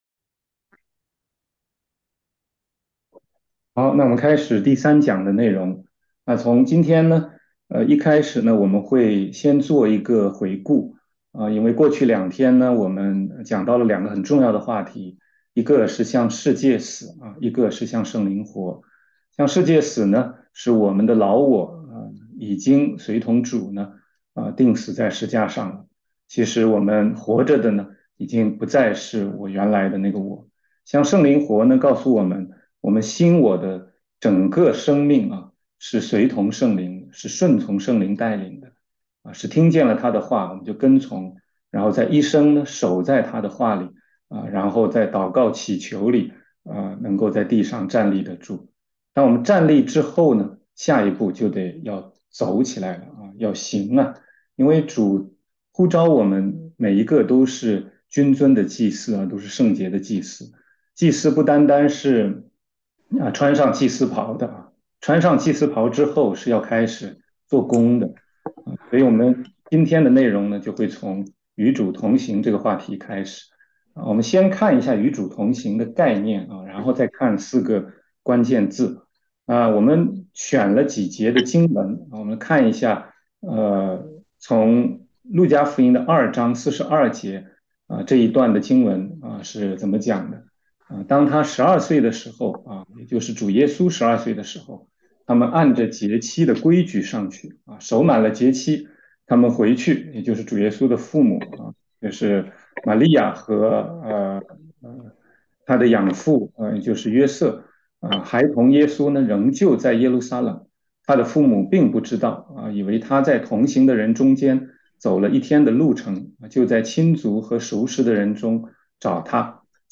16街讲道录音 - 基督徒成圣之路第三讲：与主同行